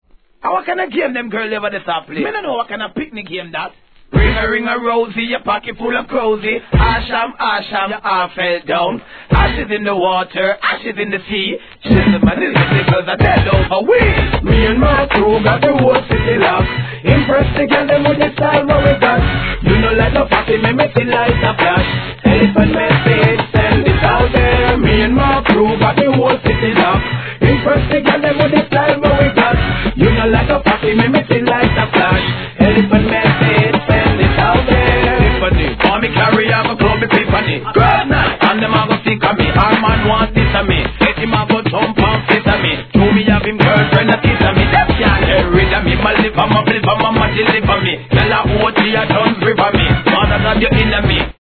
REGGAE
JAMAICAヒットのHIP HOPトラックREMIX!!